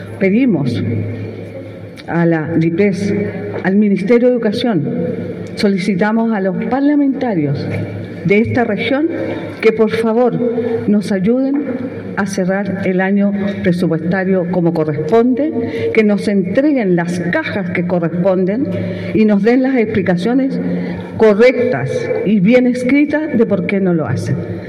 Frente a esta situación, Barraza realizó un llamado público a las autoridades nacionales para que brinden explicaciones y entreguen los recursos necesarios.